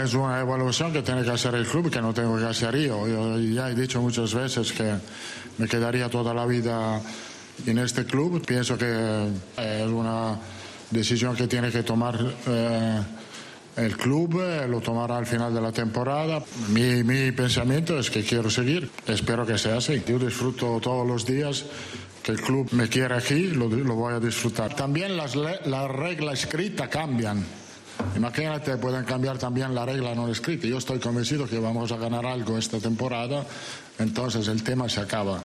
Rueda de prensa | Real Madrid